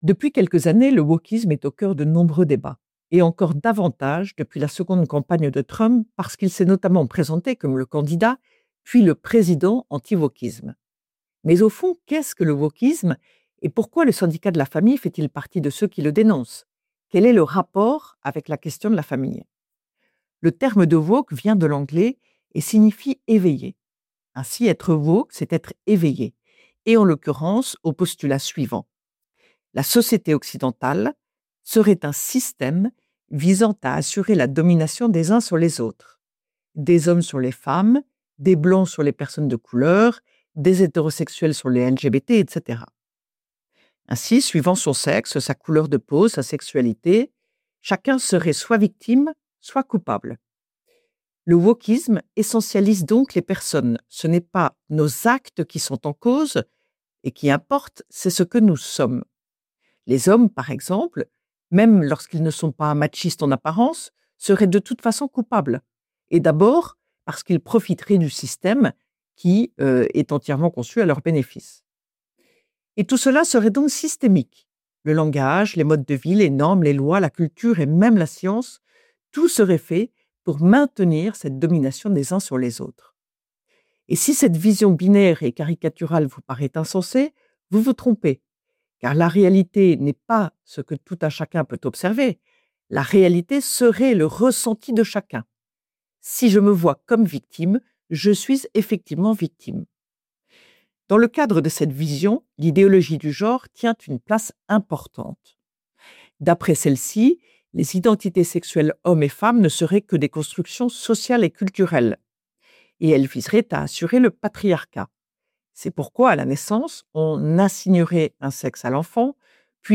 « Esprit de Famille » : Retrouvez chaque semaine la chronique de Ludovine de La Rochère, diffusée le samedi sur Radio Espérance, pour connaître et comprendre, en 3 minutes, l’essentiel de l’actualité qui concerne la famille.